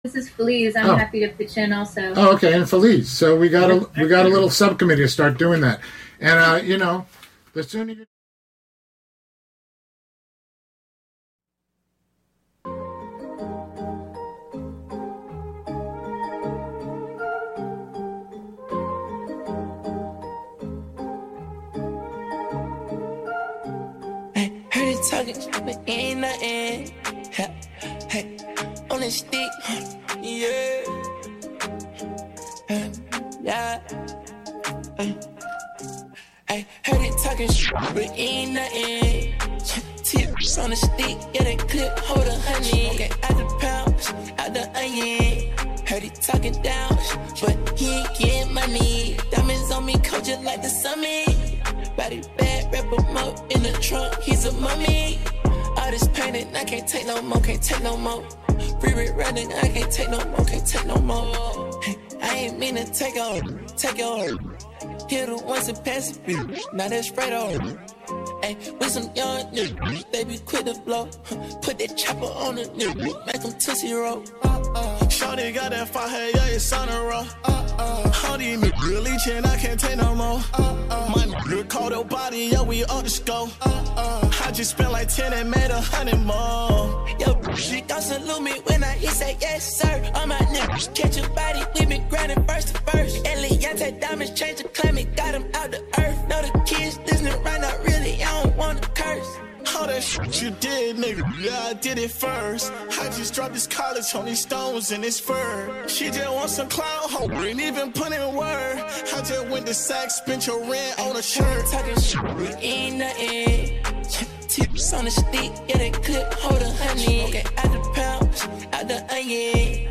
Show includes local WGXC news at beginning, and midway through.